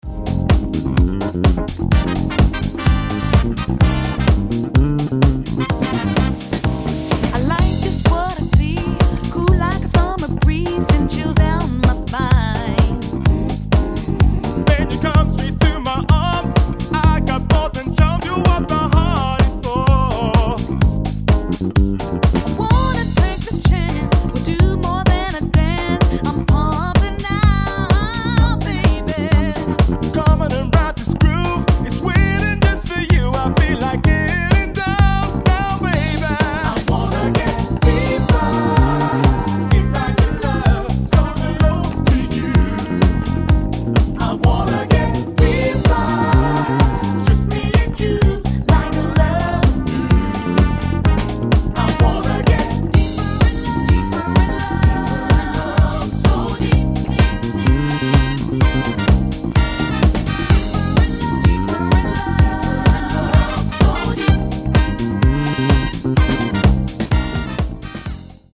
house music
a storming bass line